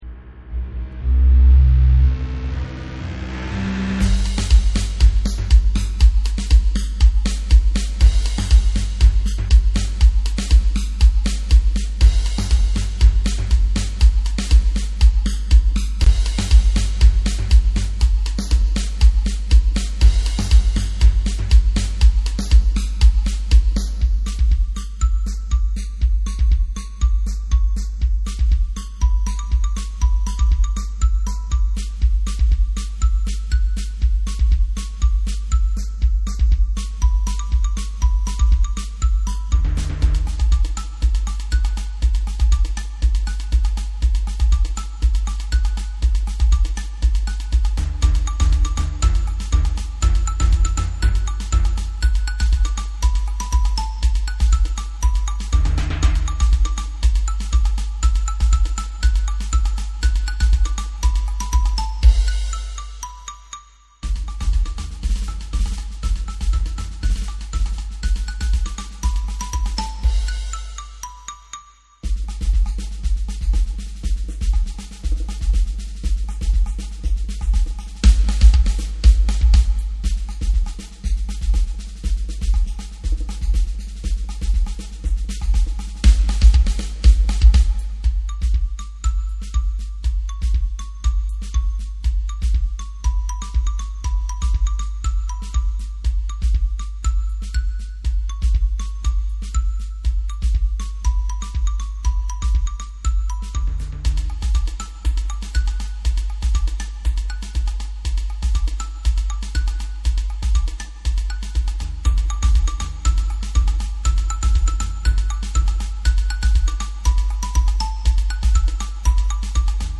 Mallet-Steelband
Bells Snare drum Shaker Bongo's Timpani Bass drum Cymbals